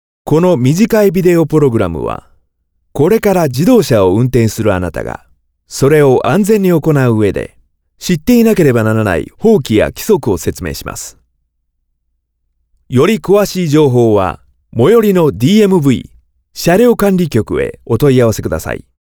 japanischer Sprecher
Sprechprobe: Industrie (Muttersprache):
japanese voice over artist